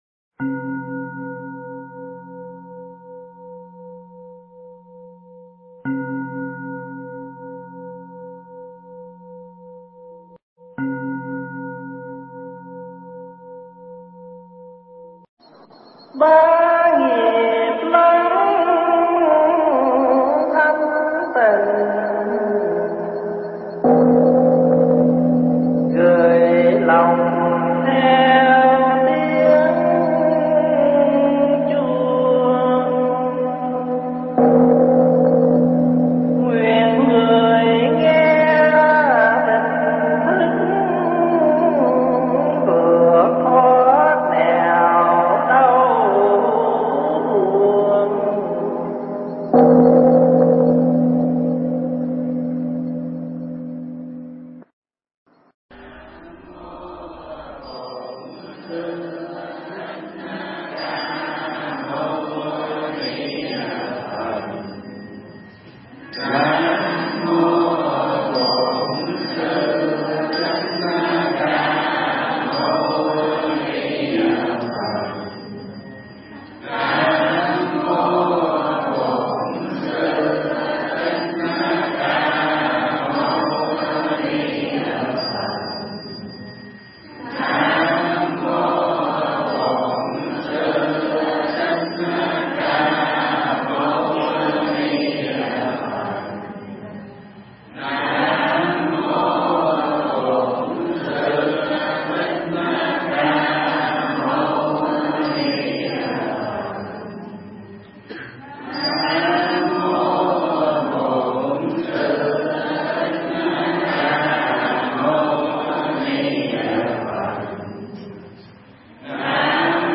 Mp3 Thuyết Giảng Người Nào Nghiệp Nấy
thuyết giảng tại Chùa Thái Perth, Úc Châu